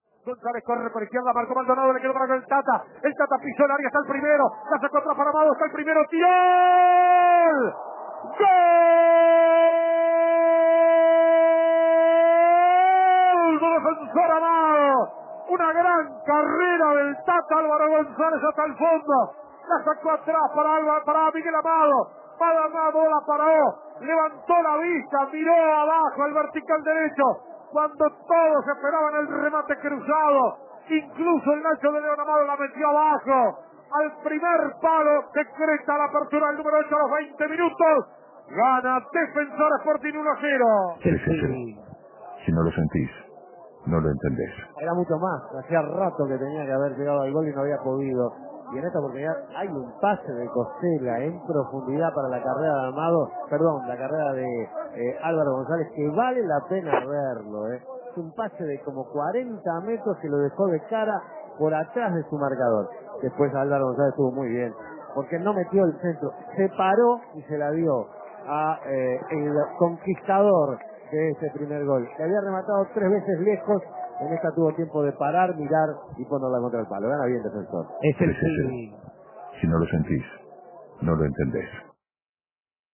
Escuche el gol del partido en el relato